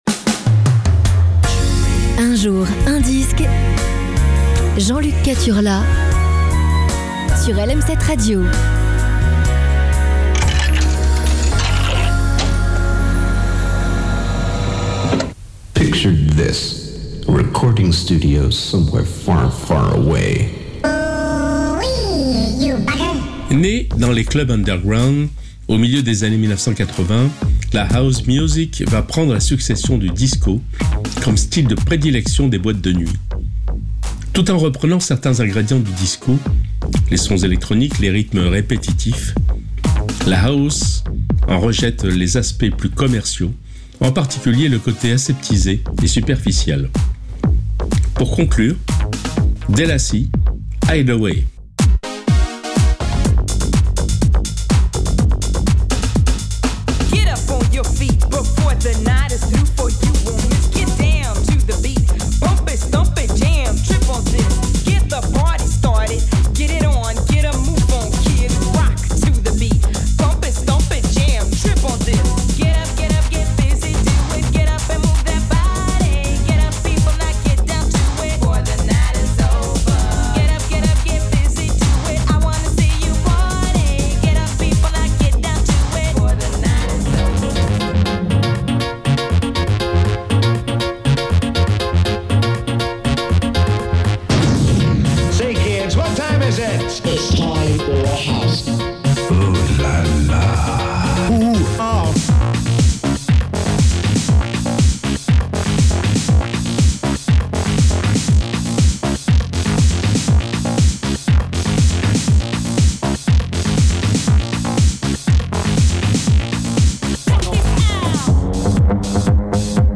aujourd'hui c'est HOUSE